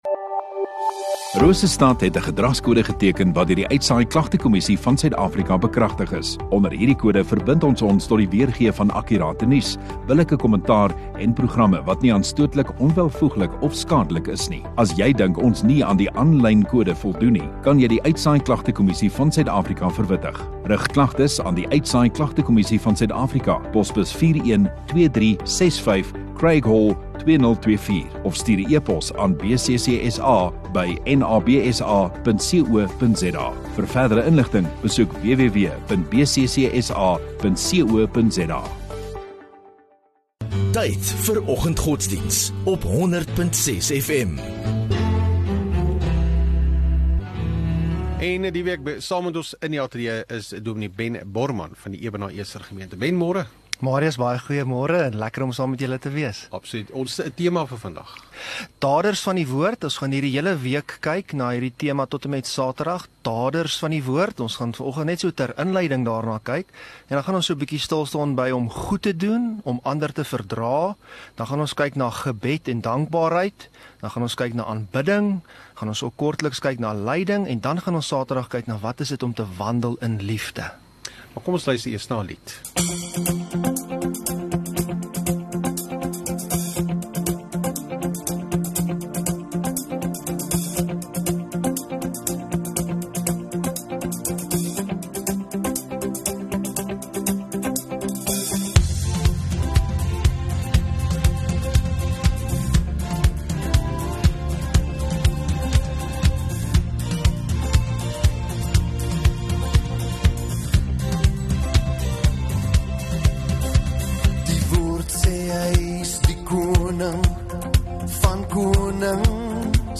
13 Jan Maandag Oggenddiens